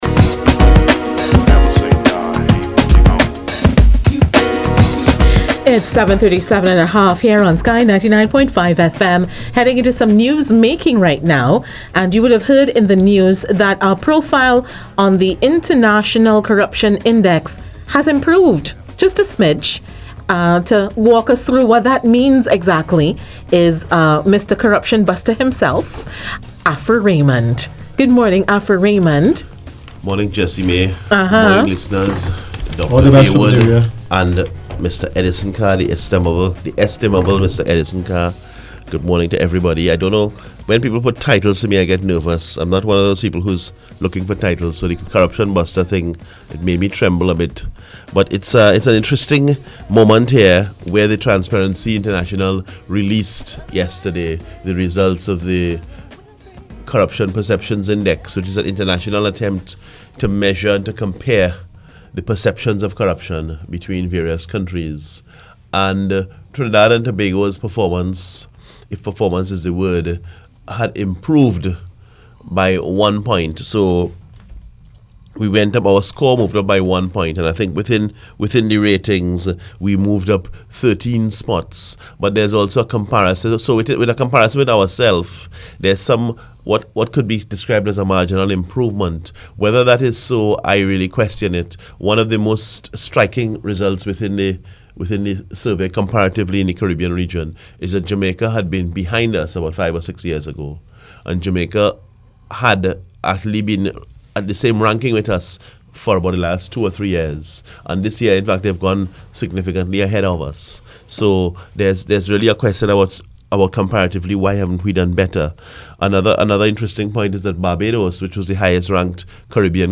AUDIO: The Breakfast Roundtable interview on Sky 99.5FM- 28 January 2016